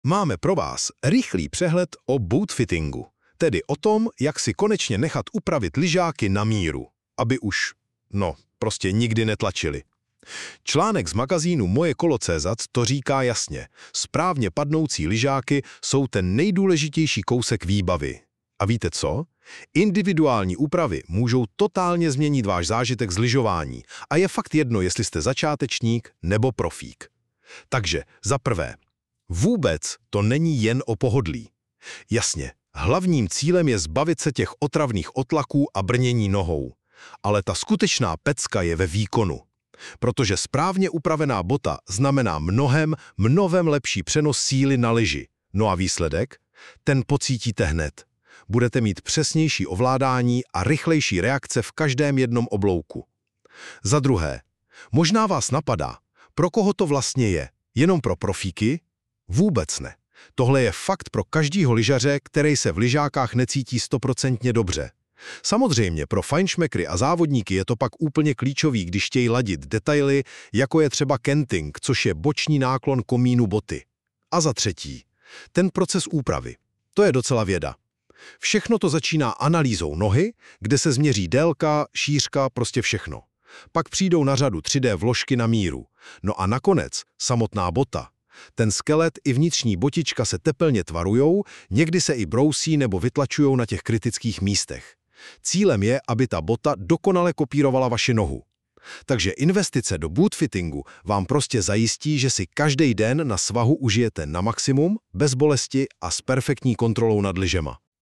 Hans AI radí